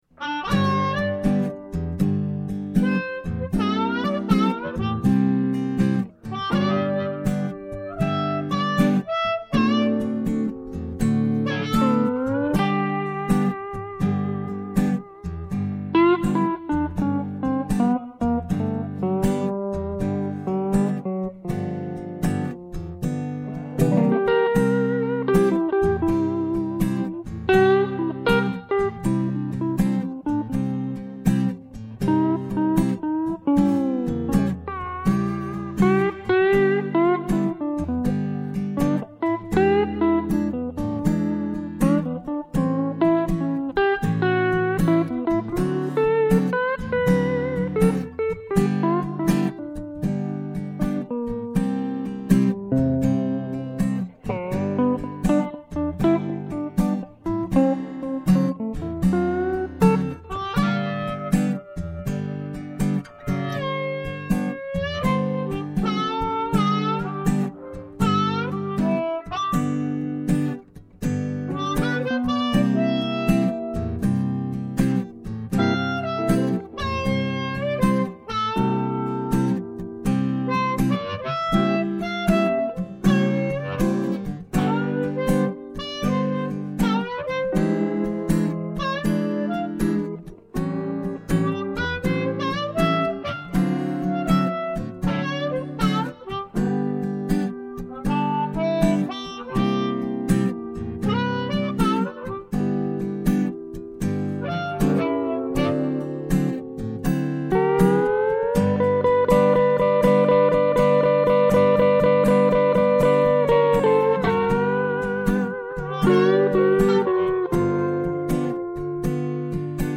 Here's an example in G on the C6th:
03-KitchenBlues.mp3